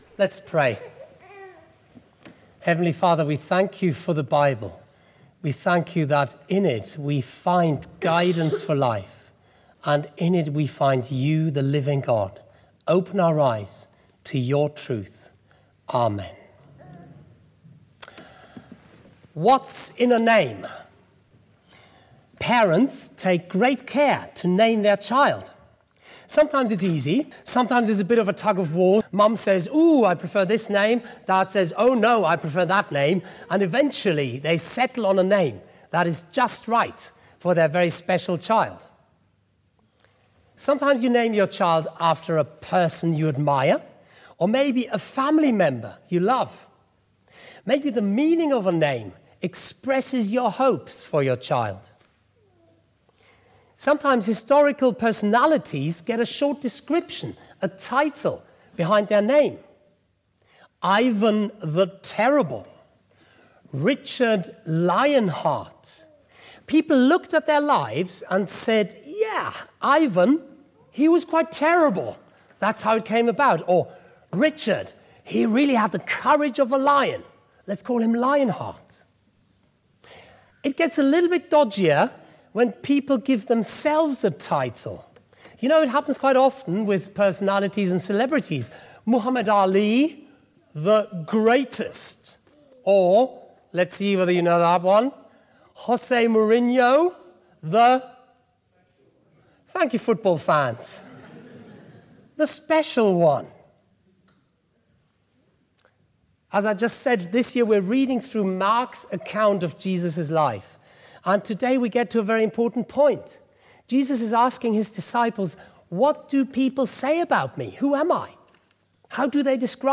180916 Sunday Service - Denham Parish Church